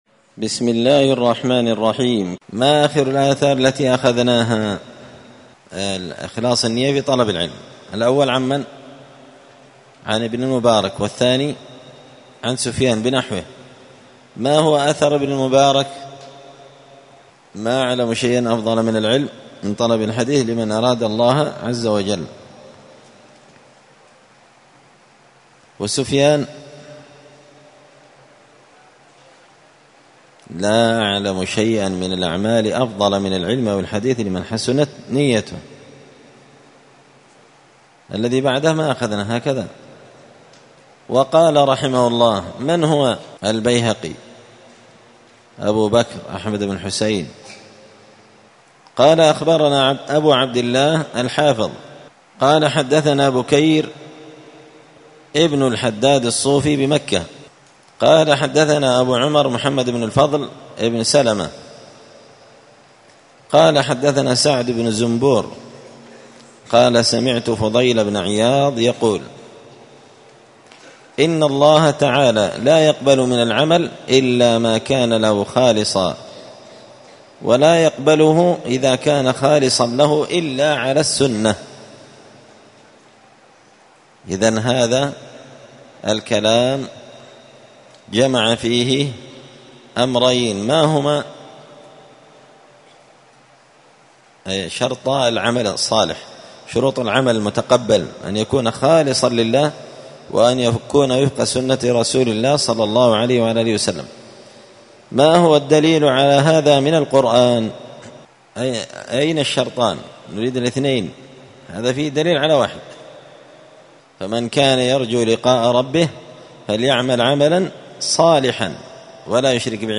دار الحديث السلفية بمسجد الفرقان